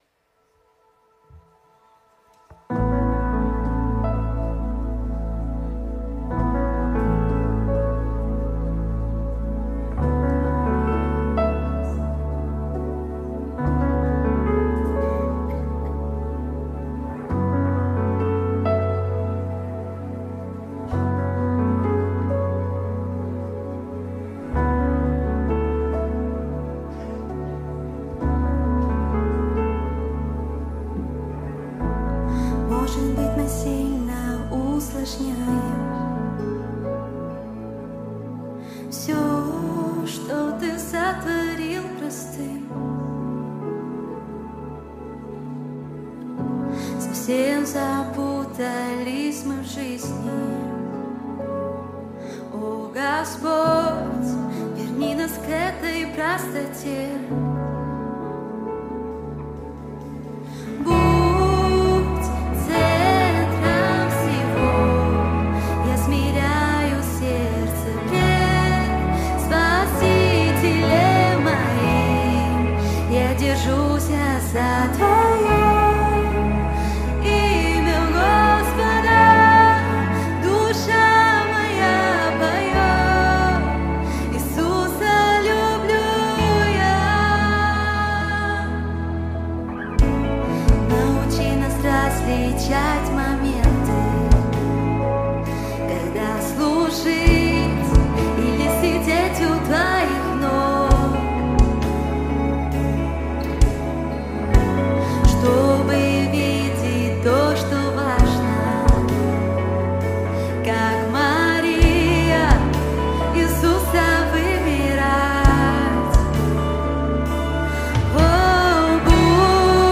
906 просмотров 746 прослушиваний 32 скачивания BPM: 60